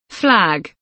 flag kelimesinin anlamı, resimli anlatımı ve sesli okunuşu